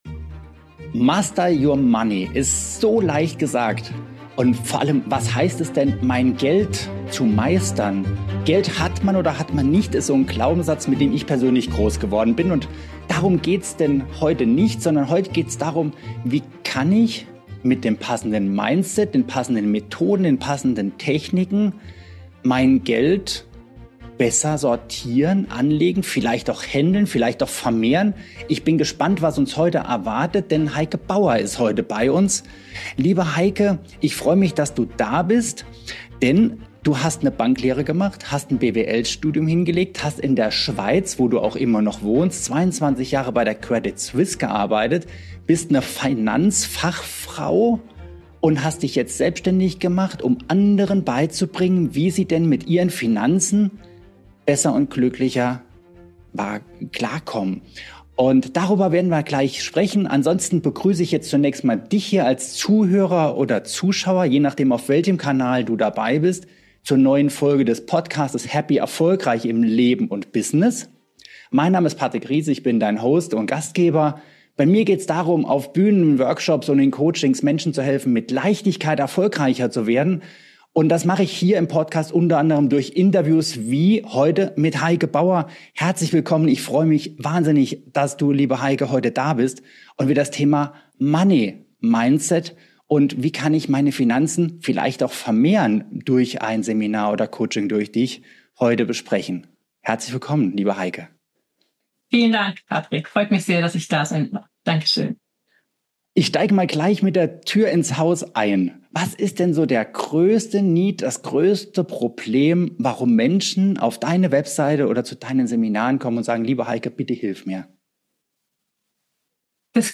Du erfährst in diesem Interview: Warum Geld nur fließt, wenn du innerlich bereit bist Wie du limitierende Glaubenssätze über Geld erkennst und löst Was „Karmic Money“ bedeutet – und warum Geben vor dem Nehmen kommt Warum finanzielle Verantwortung immer bei dir selbst beginnt Wie du konkrete Ziele setzt und mit Freude erreichst Diese Episode ist für alle, die sich ein Leben und Business in finanzieller Leichtigkeit, mit Klarheit und Sinn wünschen.